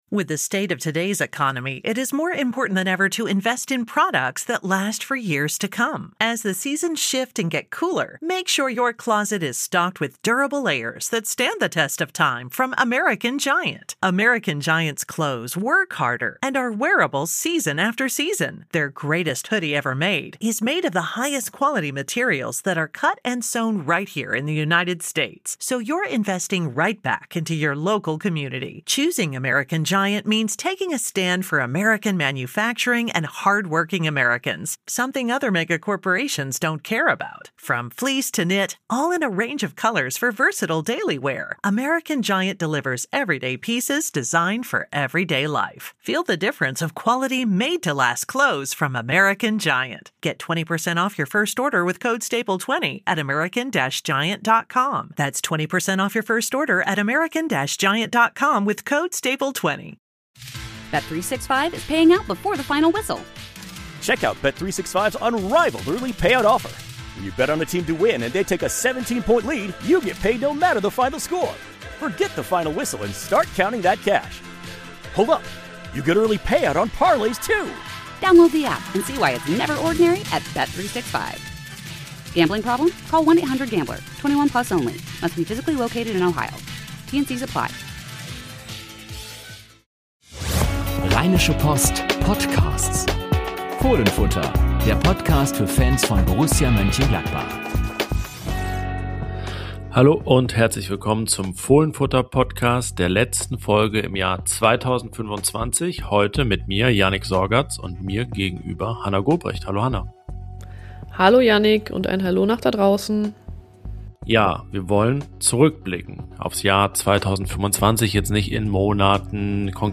Hinter Borussia Mönchengladbach liegt ein großes Umbruchs-Jahr. Unsere Reporter blicken auf 2025 Jahr zurück und diskutieren die verschiedensten Themen auf Grundlage einiger Leitfragen.